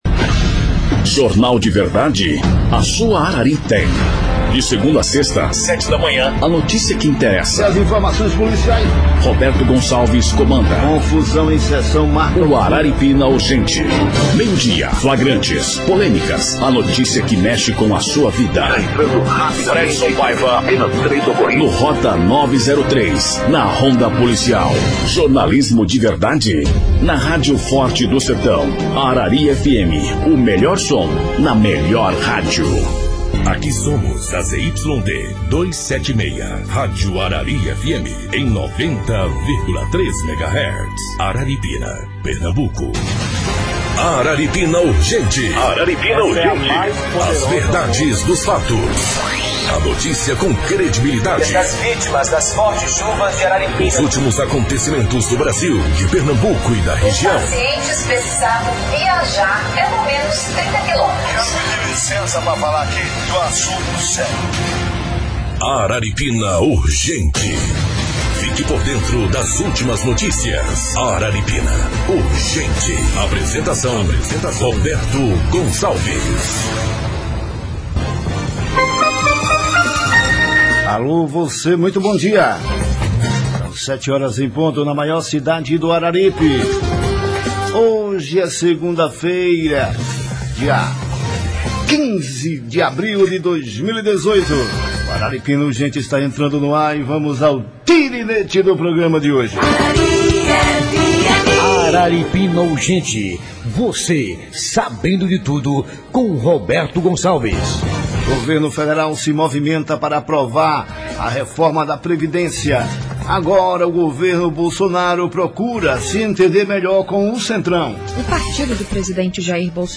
Entrevista I
Participação do ouvinte pelo WhatsApp